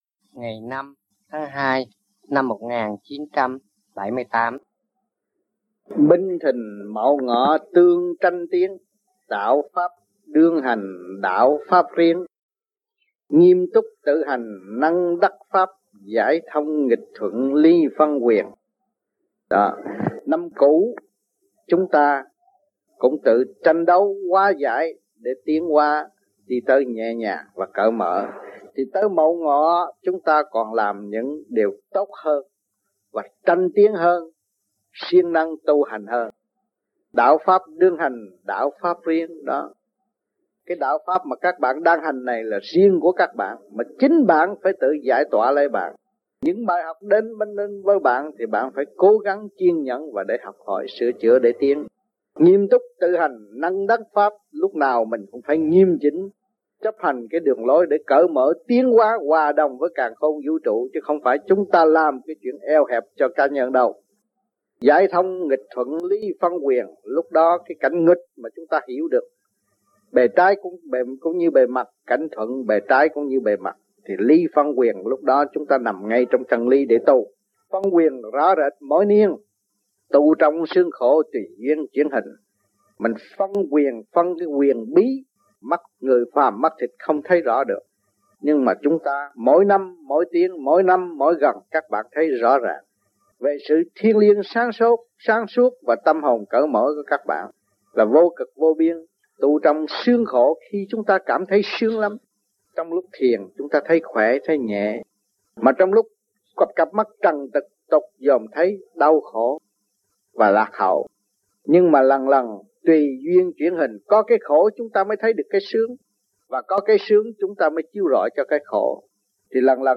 VẤN ĐẠO
THUYẾT GIẢNG